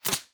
bullet5.ogg